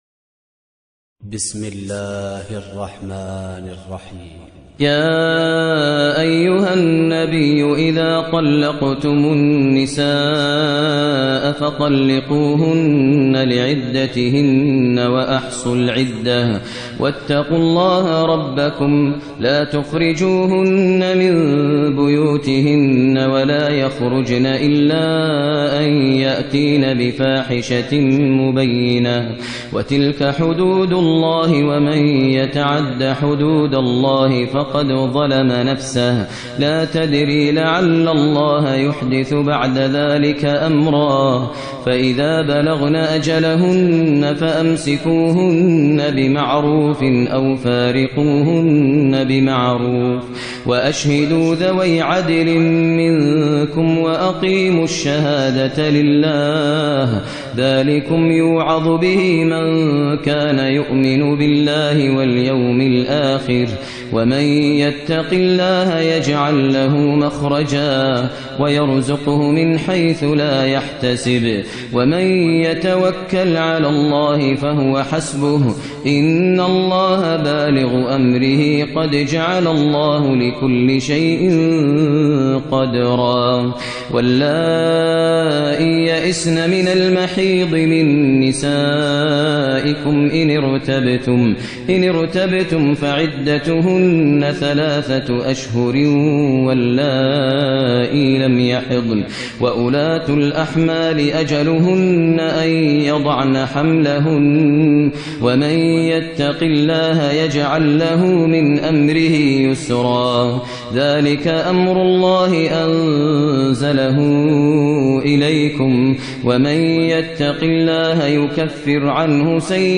ترتیل سوره طلاق با صدای ماهر المعیقلی
065-Maher-Al-Muaiqly-Surah-At-Talaq.mp3